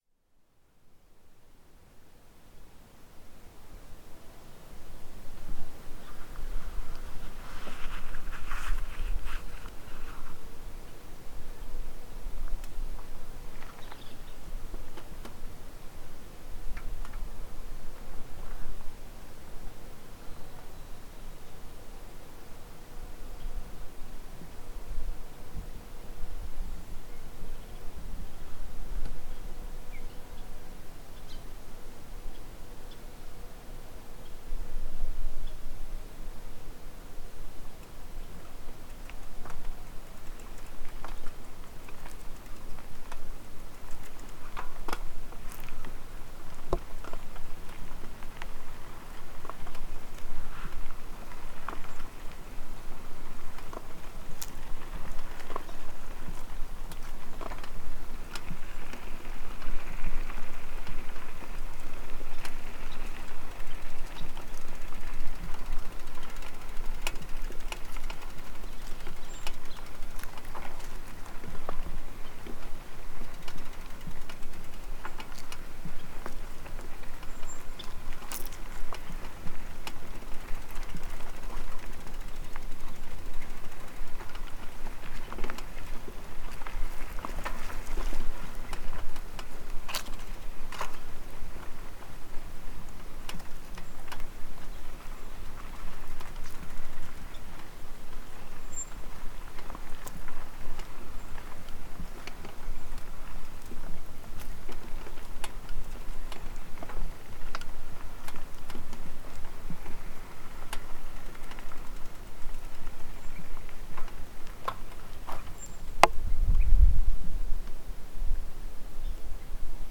Recorrido en Bicicleta
Este es un recorrido en bicicleta en uno de los caminos de "carreta" que se encuentran, parafraseando al filósofo Jose Alfredo Jimenez, alejado del bullicio y de la falsa sociedad.
El recorrido grabado poco más de 200 metros al final comienzo a acercarme a lugares poblados y el nivel de sonido comienza a incrementar.
Lugar: Nandayalú, ejido de Suchiapa.